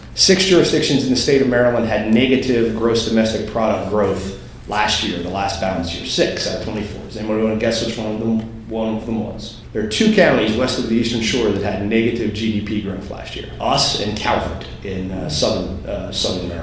At the annual legislative breakfast sponsored by the Allegany County Chamber of Commerce, the focus was the state’s budget deficit and how it will affect the state’s three westernmost counties.  House Minority Leader Jason Buckel pointed out a major shortfall for Allegany County…